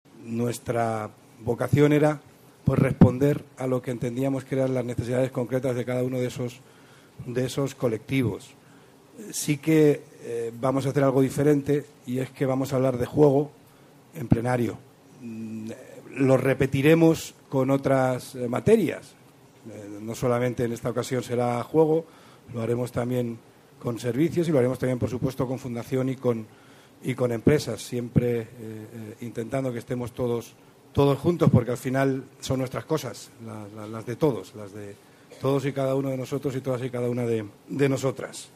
Los pasados 30 de junio y 1 de julio se celebró en Madrid reunión del Comité de Coordinación General (CCG) de nuestra Organización.